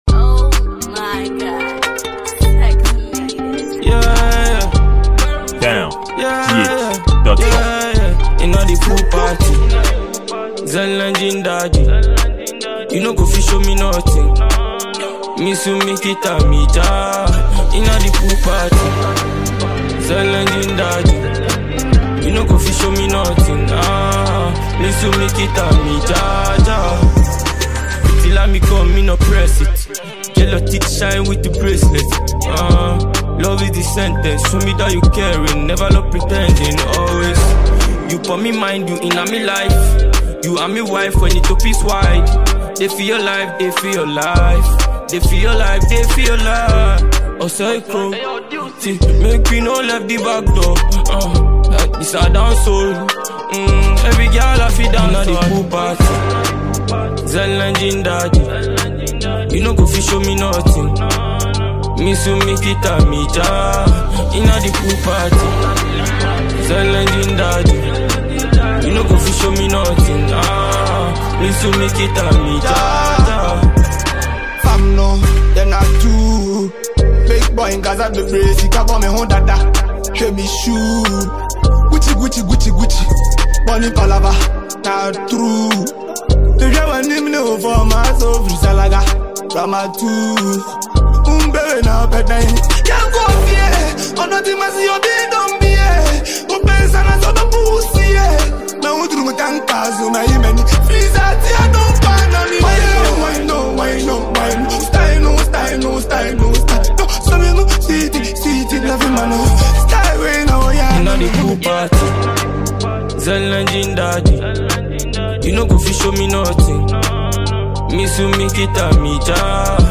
an afrobeat dancehall Ghanaian musician.